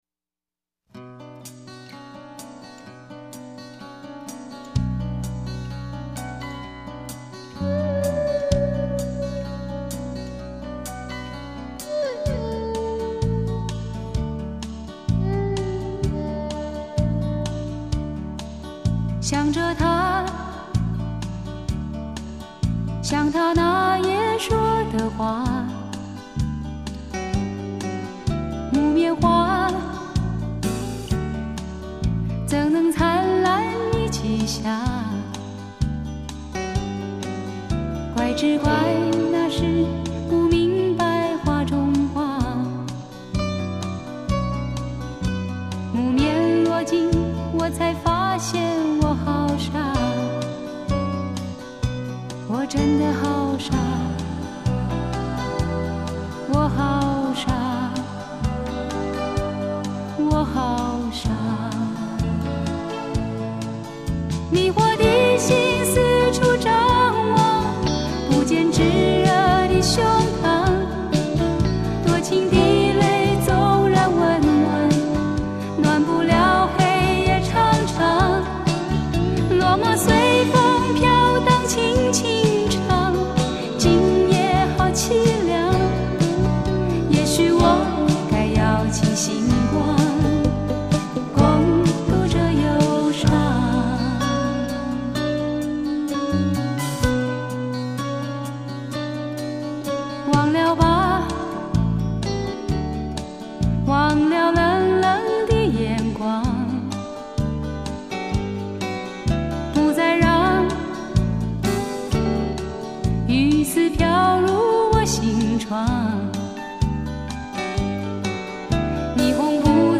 有人评说她的声音就像花开的声音，给人一种清凉婉约、淡雅圆润的感觉。